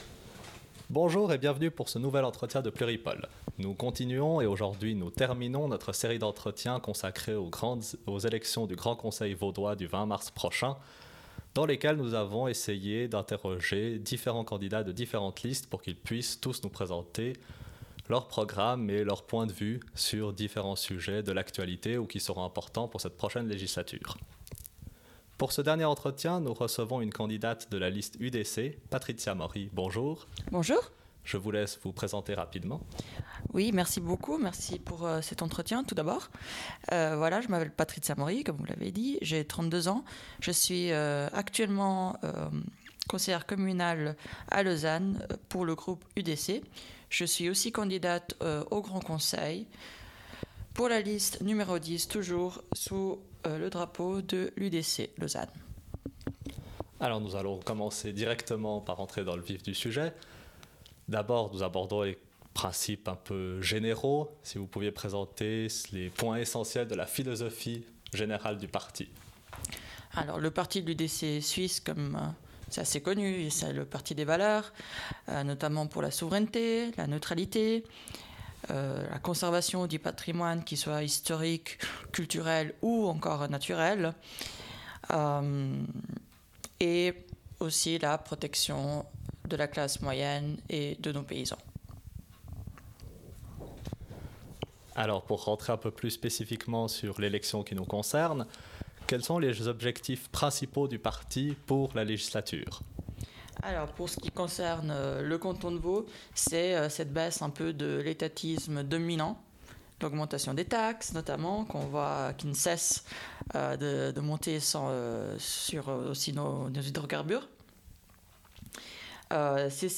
[ENTRETIEN] Élection du Grand Conseil vaudois